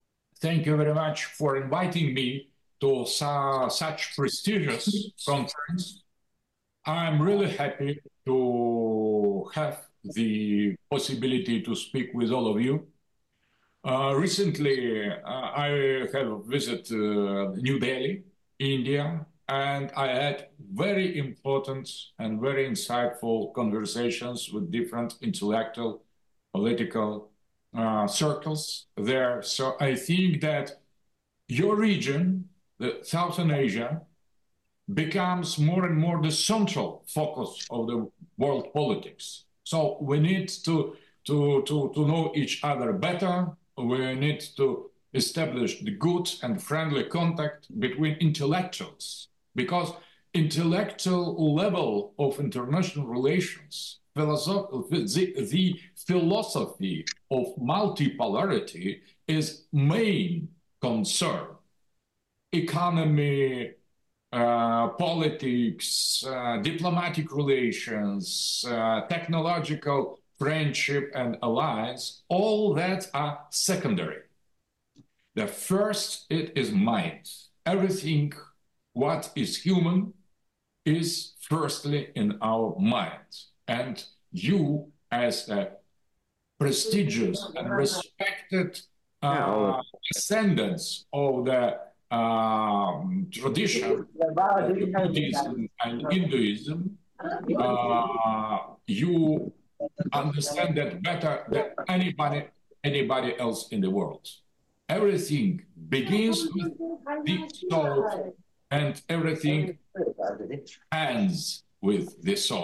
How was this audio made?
1 Speech at Conference